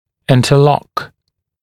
[ˌɪntə’lɔk][ˌинтэ’лок]соединяться, сцепляться; смыкаться; зацепление; сцепление